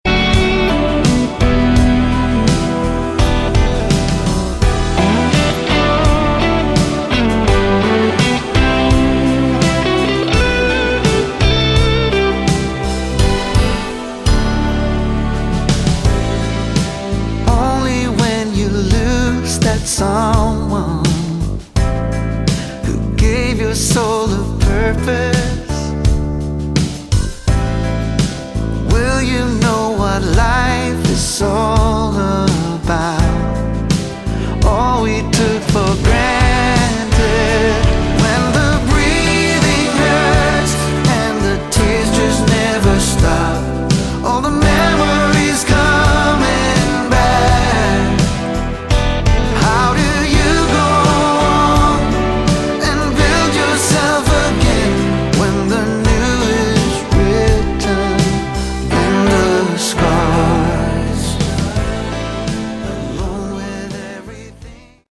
Category: Melodic Rock
guitars, bass, keyboards, backing vocals
lead vocals